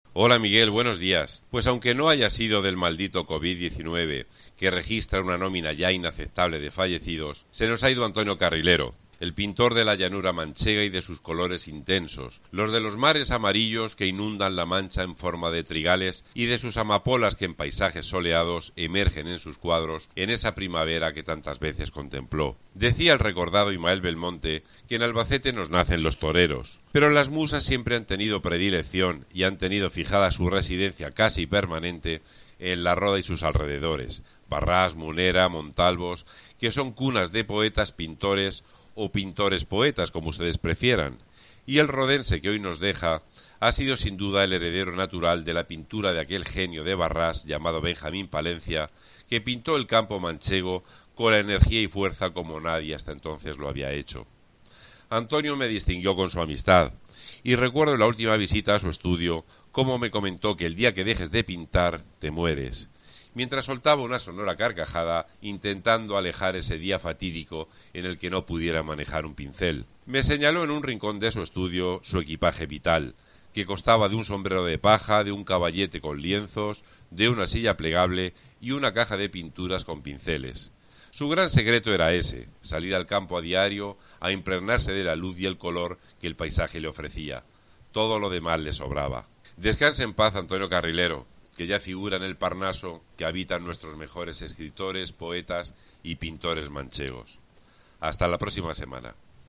su columna radiofónica de este míercoles